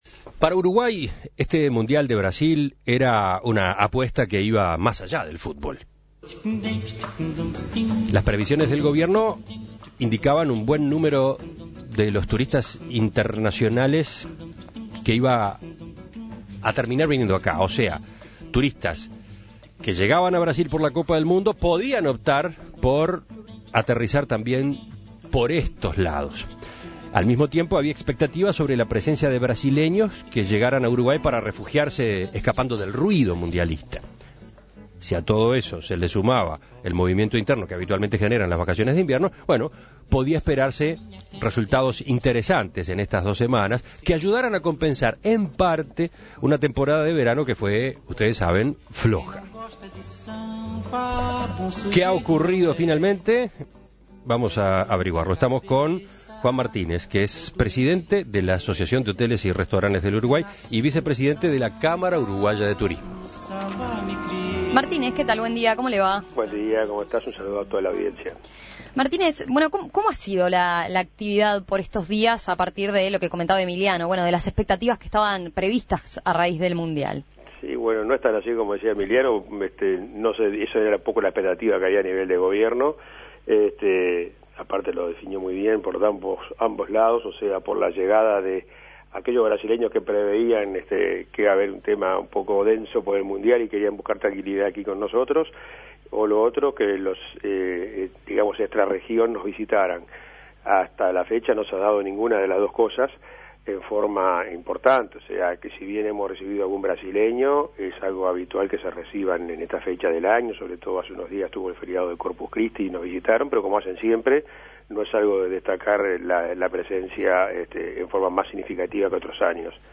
En Perspectiva entrevistó